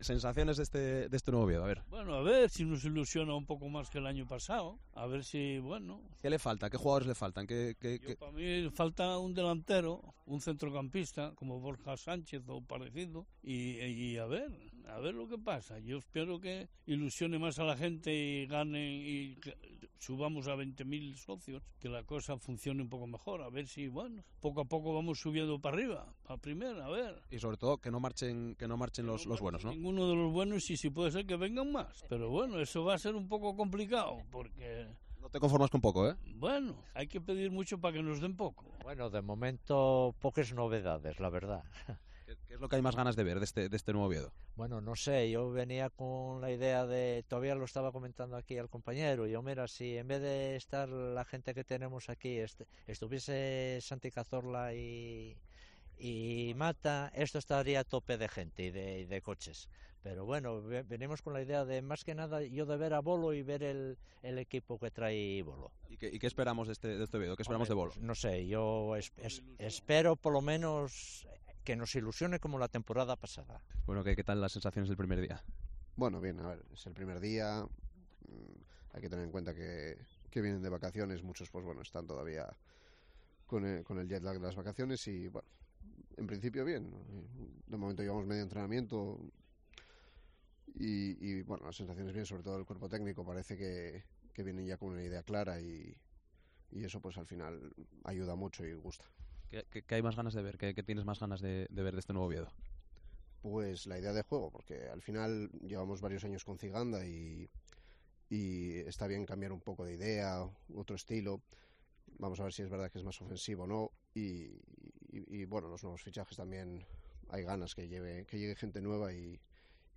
Un centenar de aficionados estuvieron viendo el primer entrenamiento del conjunto azul en el Requexón
La opinión de la afición del Real Oviedo